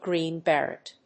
アクセントGréen Berét